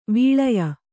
♪ vīḷaya